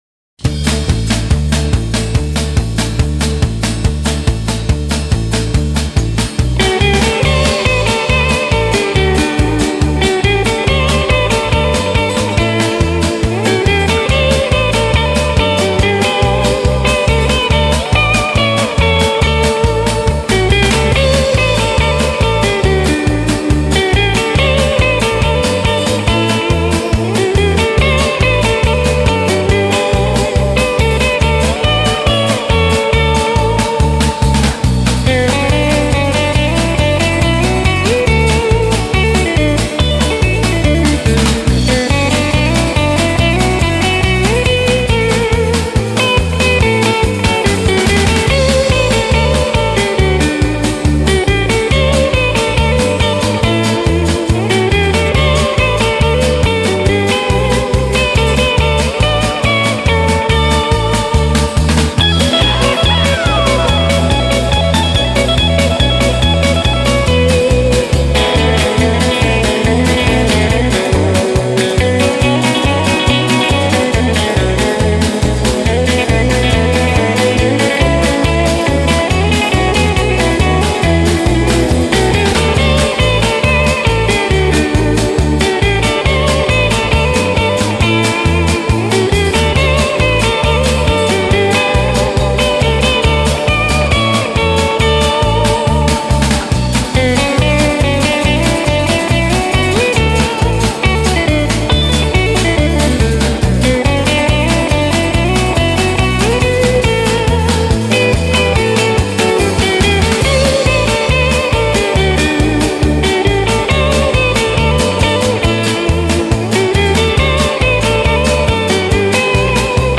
кантри-рауталанка